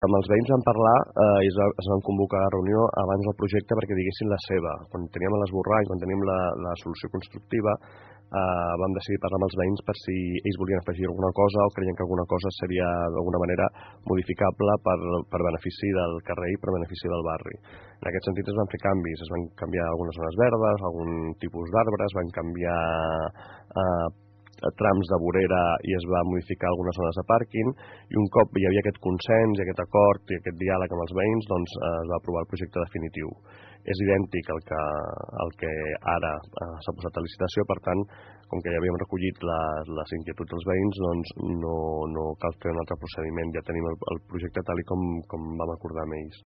Segons explica Josep Rueda, regidor d’Urbanisme de l’Ajuntament, malgrat els moviments en el procés d’adjudicació, el projecte és el mateix que es va acordar amb els veïns.